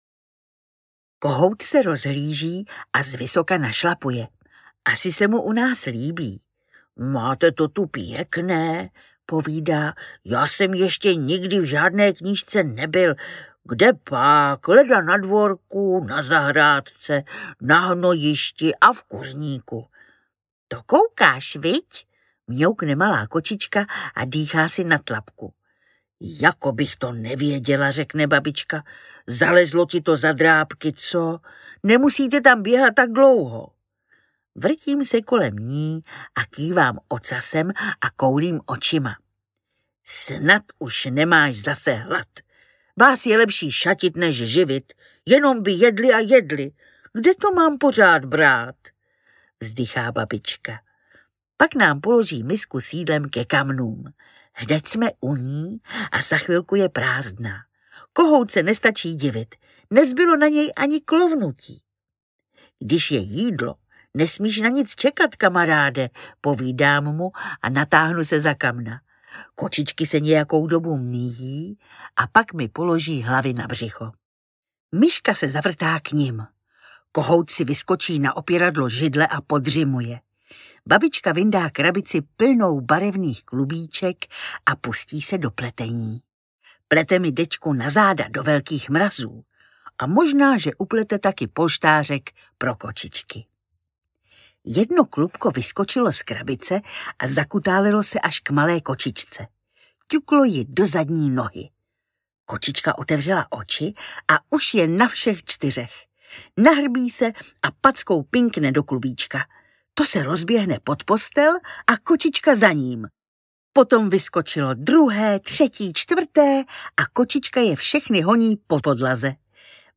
Knížkový pejsek audiokniha
Ukázka z knihy
• InterpretNaďa Konvalinková
knizkovy-pejsek-audiokniha